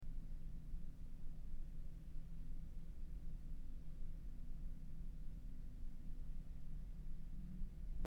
Room Tone
Room_tone.mp3